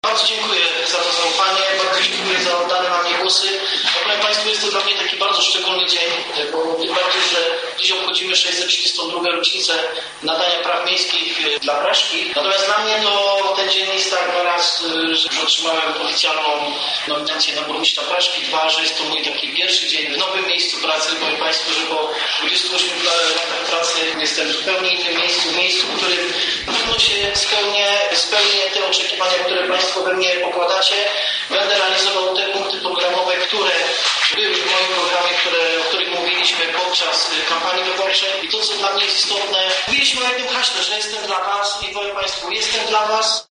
– mówił burmistrz Praszki, Włodzimierz Stochniałek.